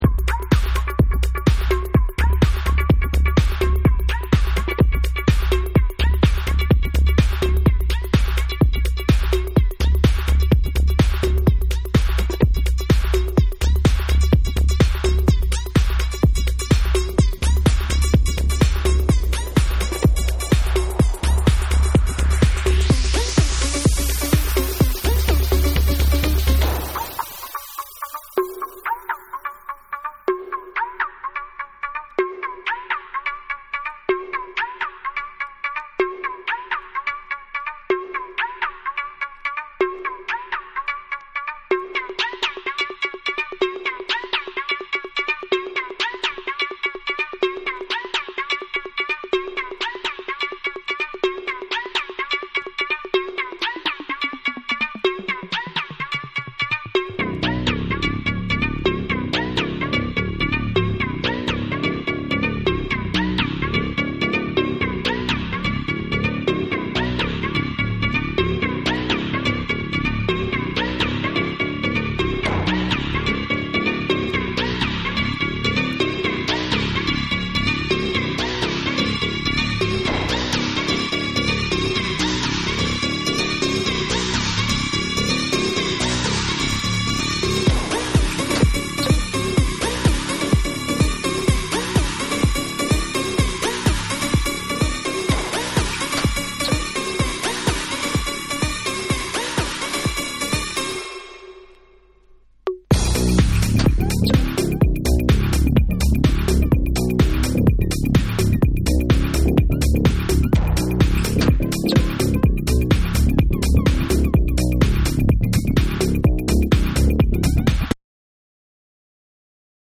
ブレイクからの破壊力が凄まじ過ぎる完全フロア向きなサイケデリック・ミニマル